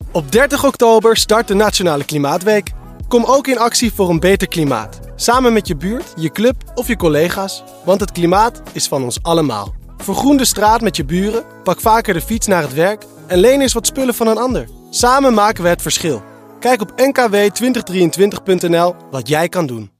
Radiocommercial
Voor de landelijke communicatie en aankondiging van de Nationale Klimaatweek. Is vooraf en tijdens de week een radiocommercial ingezet op landelijke zenders zoals Qmusic.